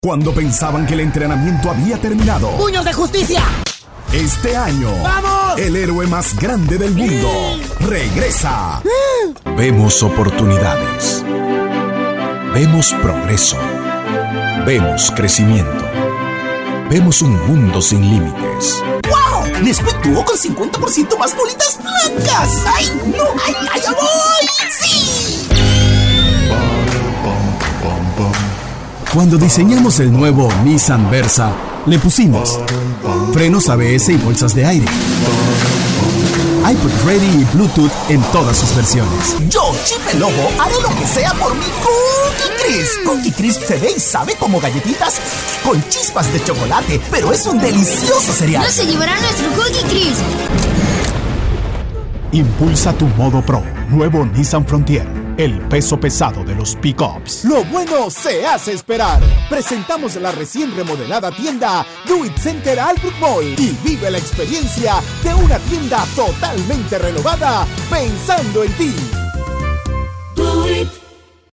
Locutor Comercial, Ingeniero de Sonido y Especialista en Sistemas Broadcast con 32 años de experiencia y más de 10,000 piezas comerciales trabajadas tanto local como internacionalmente.